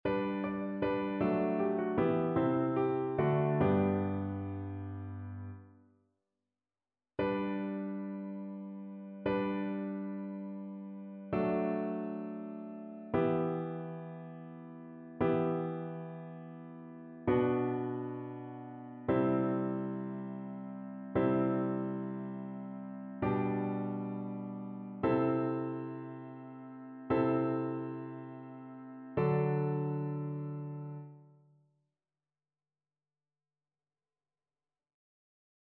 ChœurSopranoAlto
annee-b-temps-ordinaire-23e-dimanche-psaume-145-satb.mp3